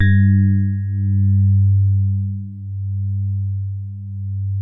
FINE SOFT G1.wav